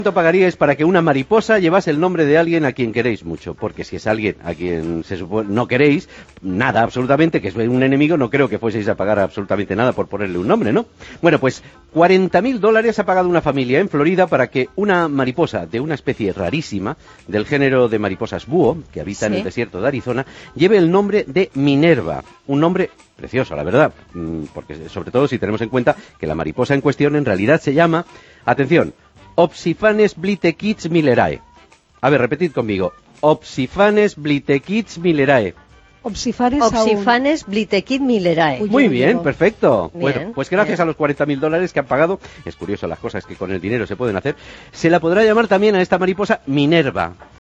Entreteniment
FM